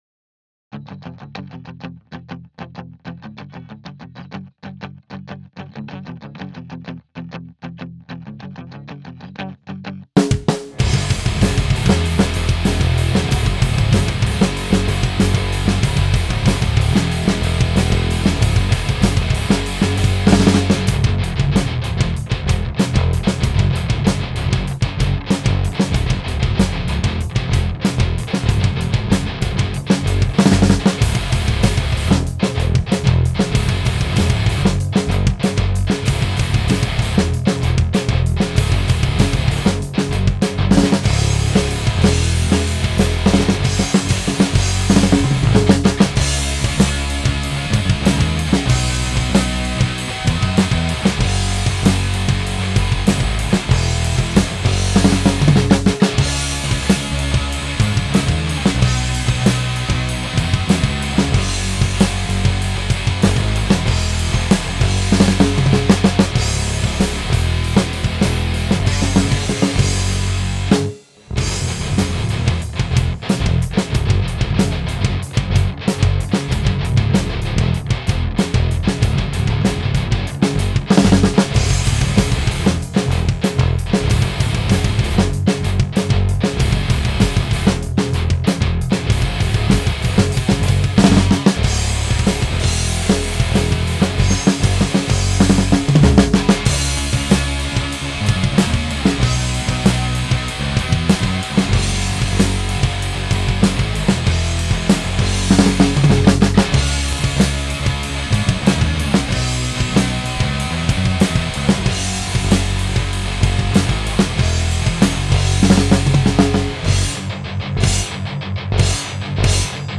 This is an instrumental mix for a new song im writing. I just want feedback on the instruments before i put vocals to it.